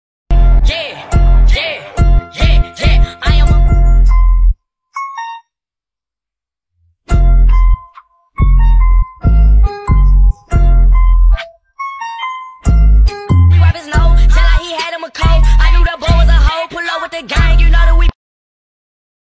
Spped Songs With No Words Sound Effects Free Download